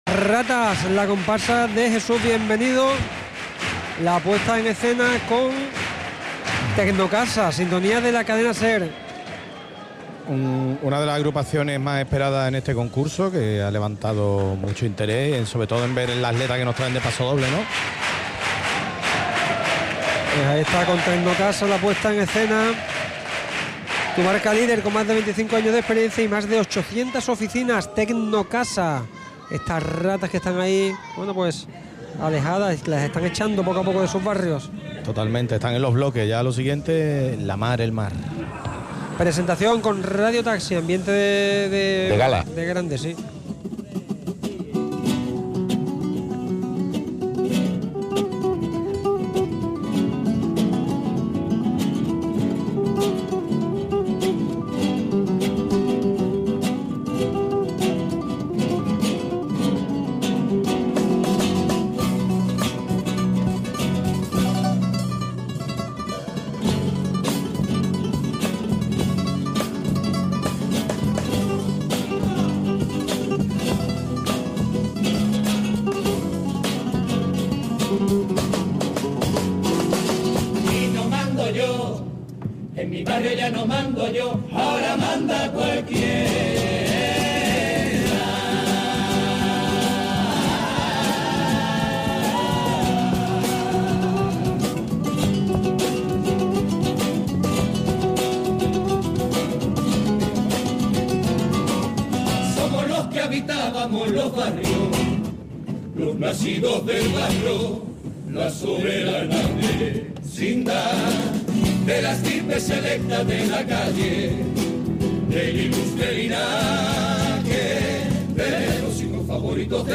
Comparsa Las Ratas Semifinal